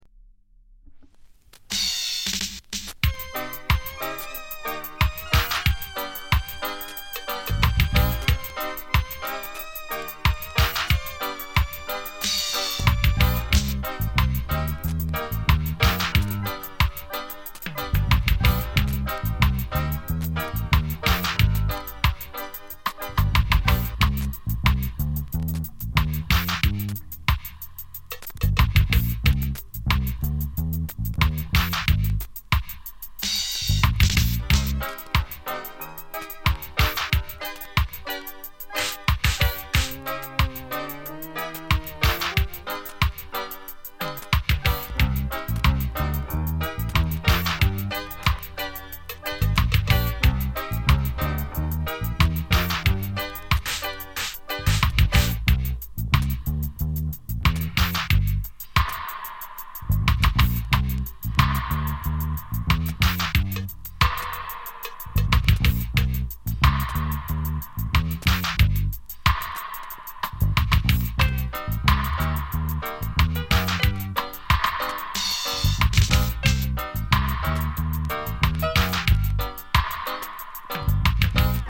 ジャマイカ盤 7inch/45s。
B面 センターずれ大。音に影響あり。